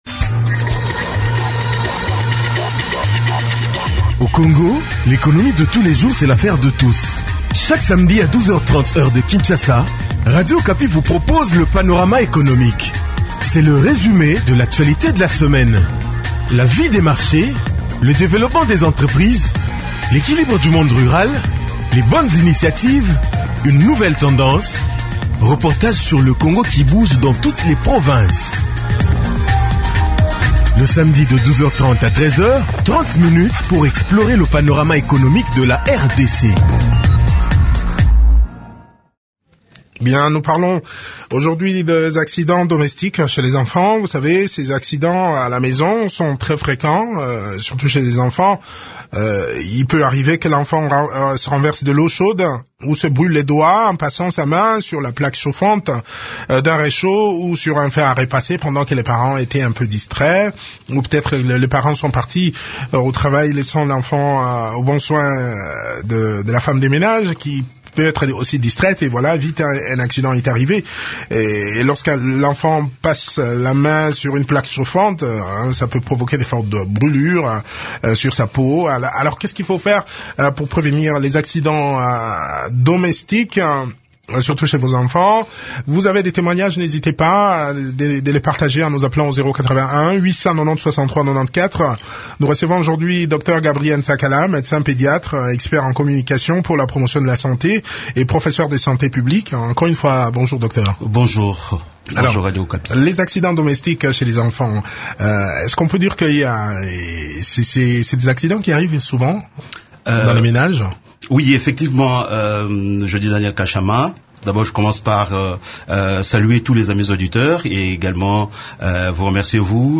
Des éléments de réponse dans cet entretien